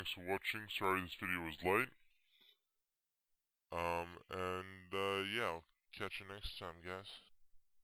robot voice